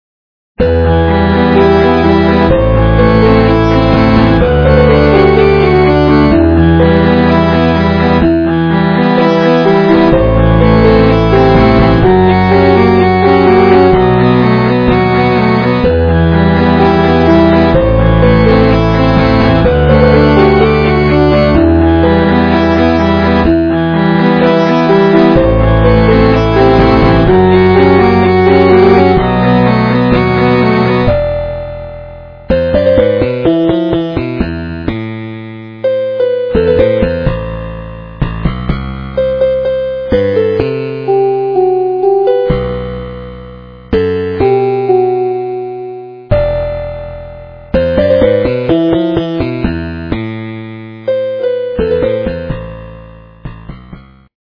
- рок, металл
полифоническую мелодию